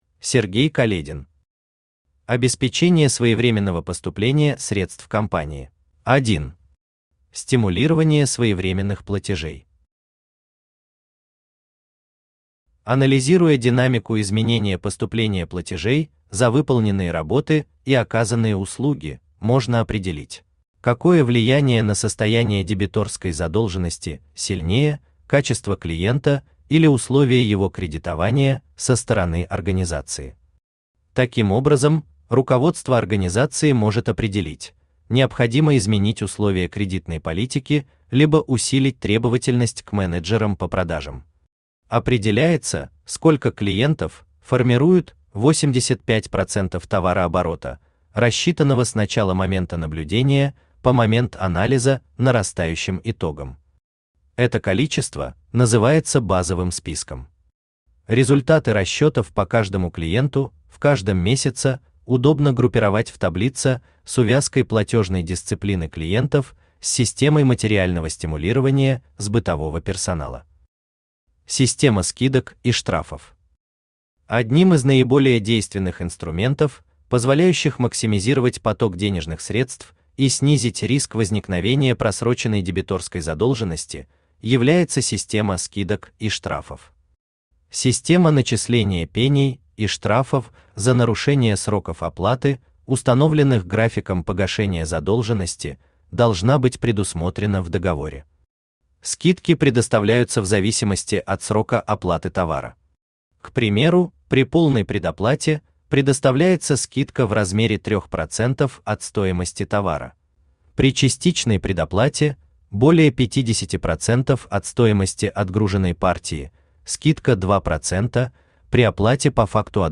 Aудиокнига Обеспечение своевременного поступления средств компании Автор Сергей Каледин Читает аудиокнигу Авточтец ЛитРес.